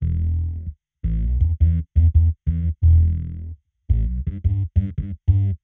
Index of /musicradar/dub-designer-samples/85bpm/Bass
DD_JBassFX_85E.wav